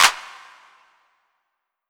• Clap Sound D Key 10.wav
Royality free clap sample - kick tuned to the D note. Loudest frequency: 3229Hz
clap-sound-d-key-10-kwG.wav